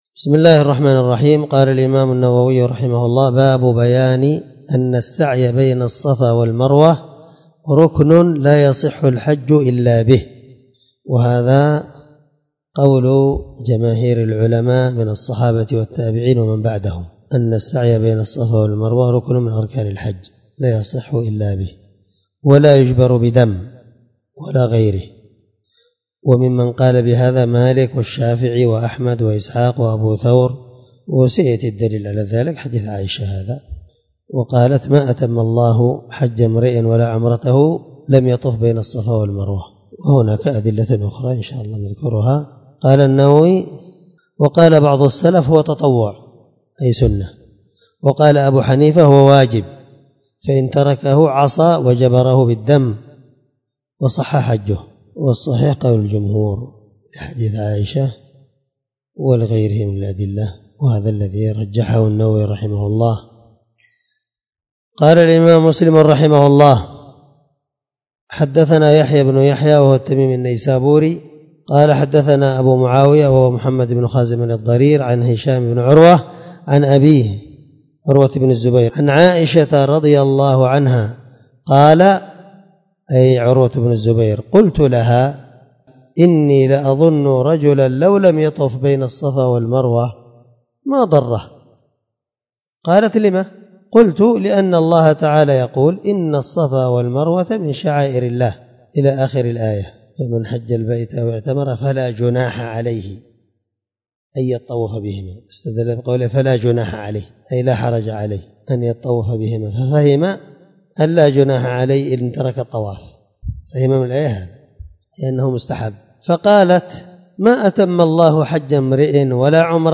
الدرس39من شرح كتاب الحج حديث رقم(1277-1278) من صحيح مسلم